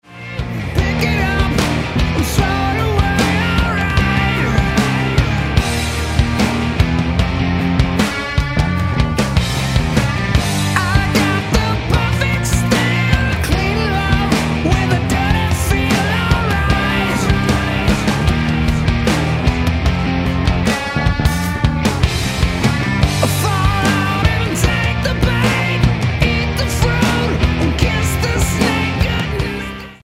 guitar
drums
bass